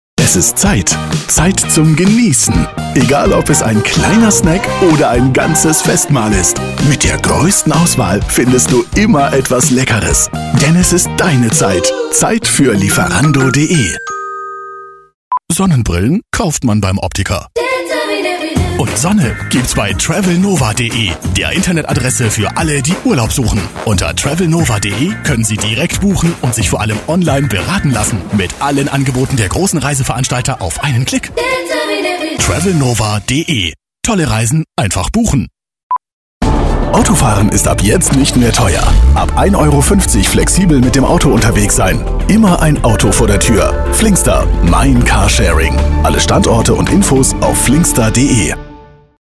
Male
Approachable, Assured, Bright, Character, Confident, Conversational, Cool, Corporate, Deep, Energetic, Engaging, Friendly, Funny, Natural, Sarcastic, Smooth, Soft, Upbeat, Versatile, Warm
Microphone: Neumann TLM 103